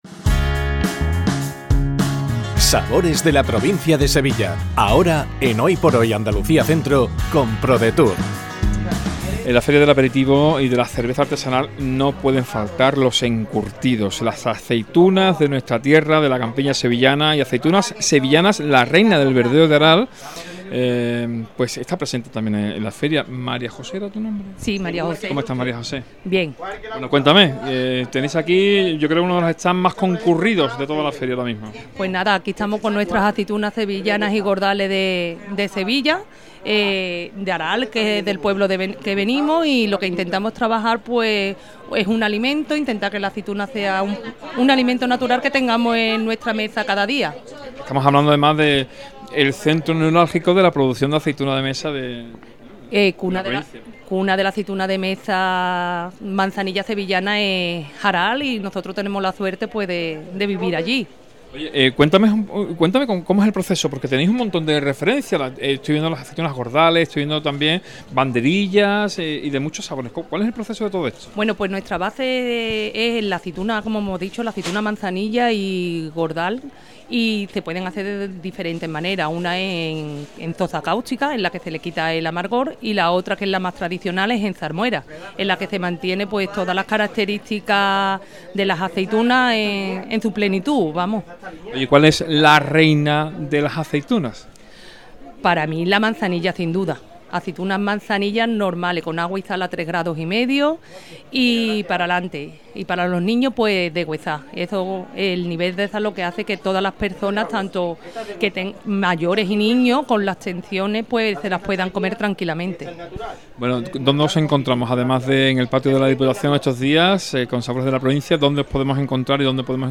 ENTREVISTA | La reina del verdeo